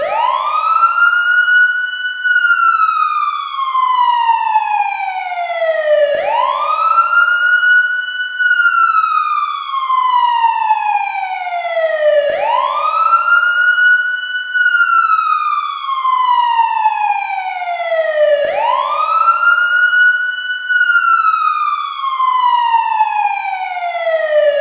sirenout.ogg